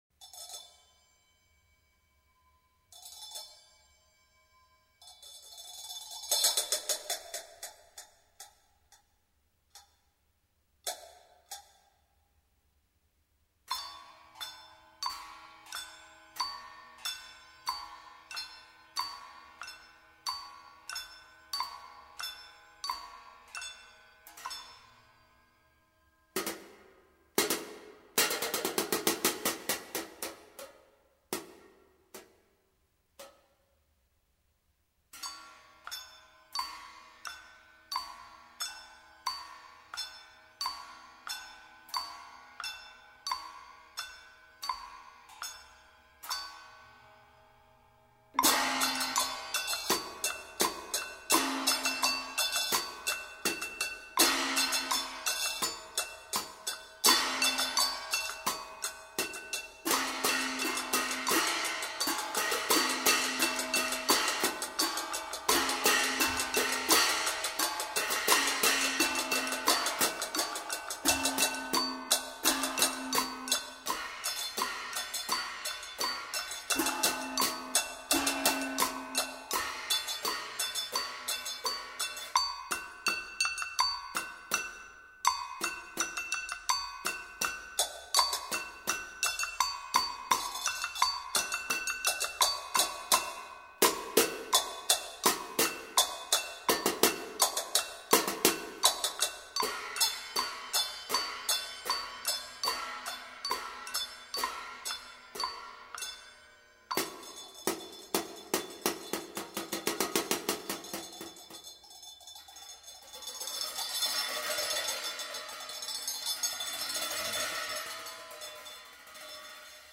performs both traditional and contemporary music